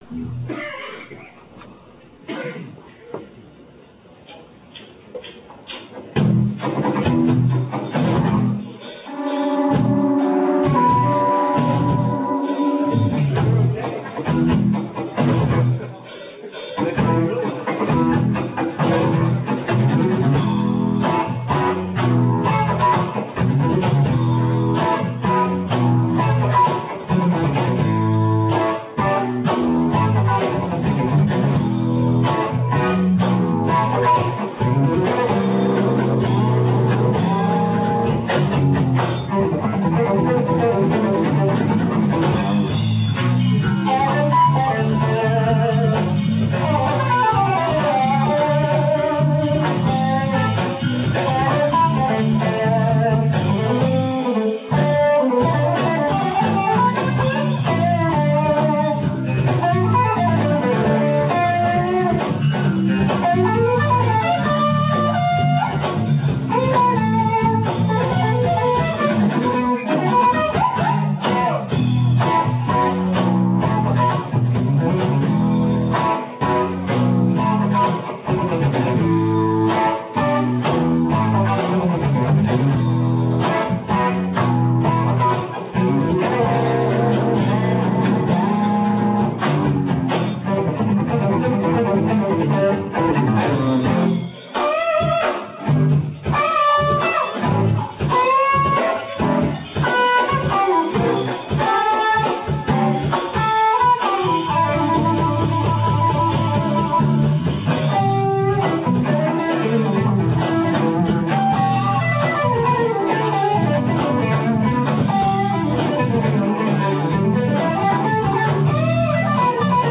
All in all some killer fusion!!